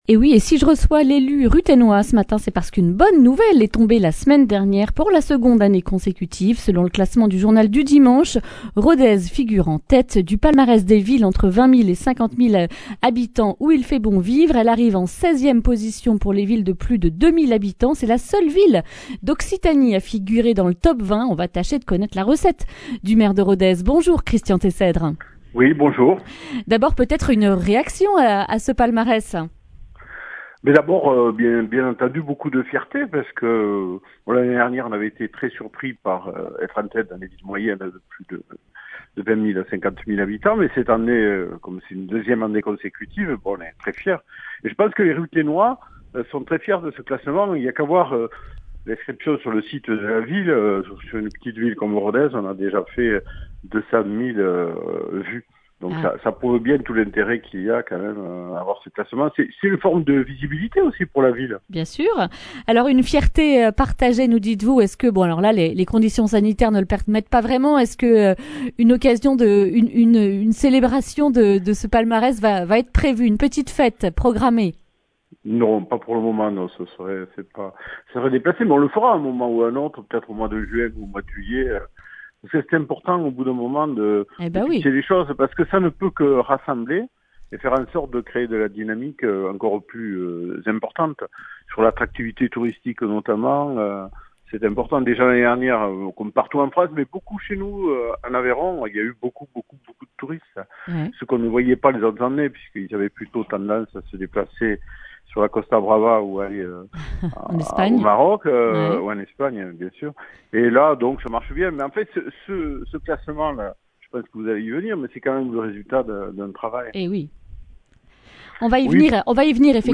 lundi 19 avril 2021 Le grand entretien Durée 10 min